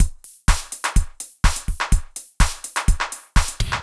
IBI Beat - Mix 1.wav